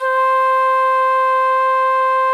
FluteClean2_C3.wav